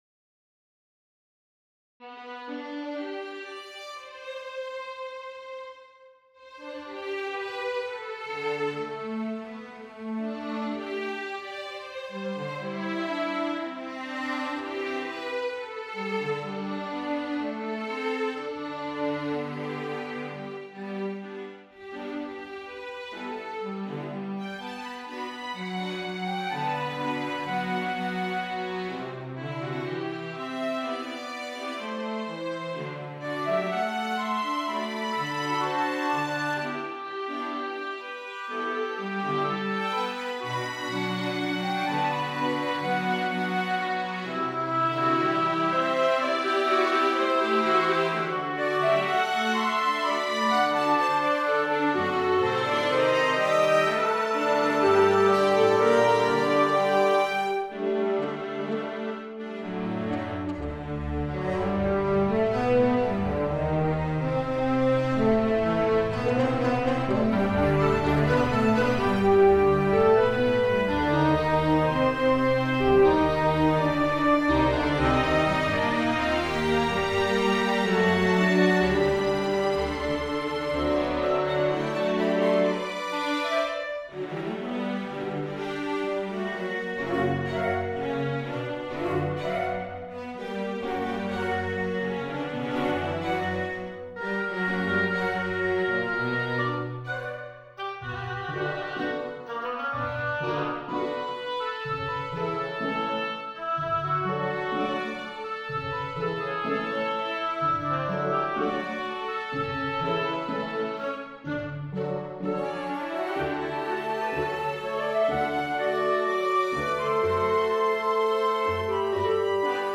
3 flutes, 3 oboes, 3 clarinets, 1 bassoon
6 French horns, 2 trumpets, 4 trombones, 3 Wagner tubas
18 first violins, 11 second violins, 10 violas, 10 cellos, 9 double basses
Harp, glockenspiel, timpani, 3 snares, cymbal, Taiko drums
The first part modulates from G major to both its parallel (g) and relative (e) minors.
The closing section (mm. 120-131) emphasizes character transition from hopeful (major and augmented chords) to somber (minor and diminished chords).
Post-processing and mixing included automation of volume envelopes and panning to reflect orchestra layout; digital filters applied (in the form of effects plugins) include reverb of the orchestra and equalization of the taiko drums (cut low and boosted high frequencies). No dynamic range compression was used, so as to preserve orchestral dynamics.